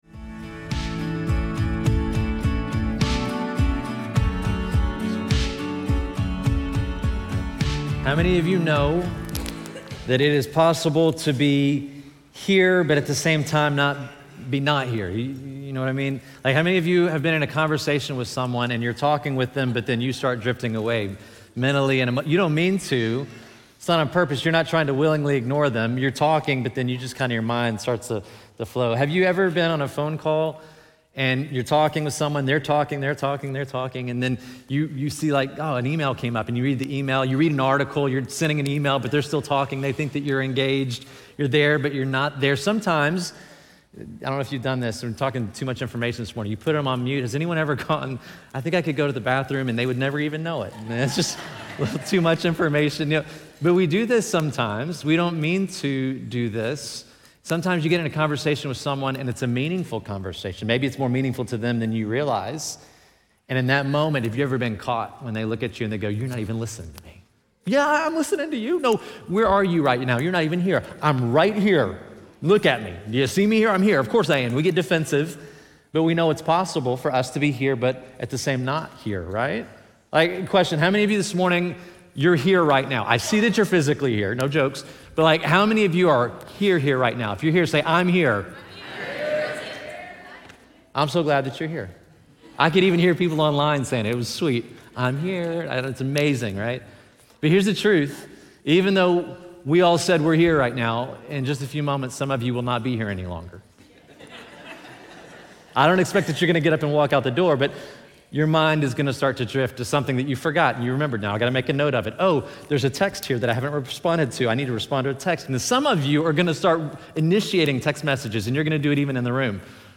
feb-9-2025-weekly-sermon-audio.mp3